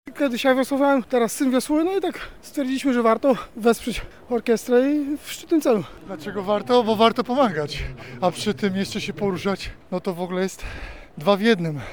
uczestnicy-.mp3